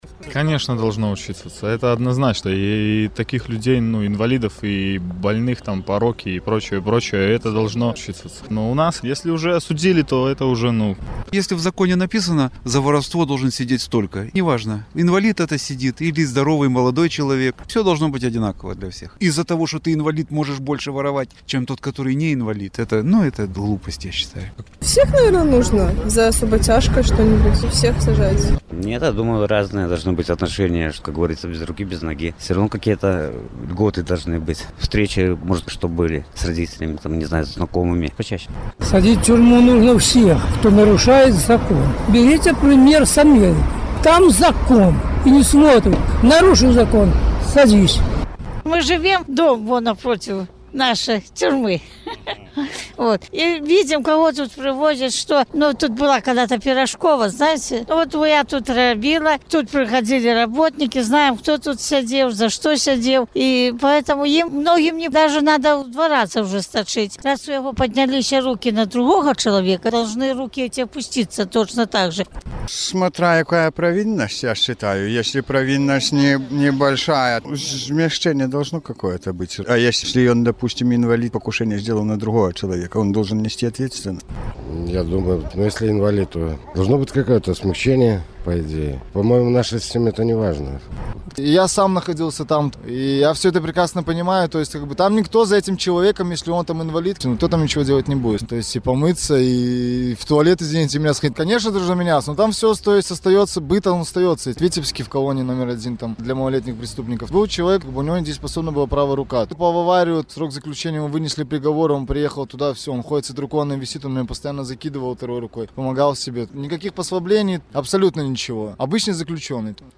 Тэма праграмы — інваліды, цяжка хворыя і нямоглыя старыя за кратамі. У перадачы ўдзельнічаюць былыя сядзельцы беларускіх папраўчых устаноў.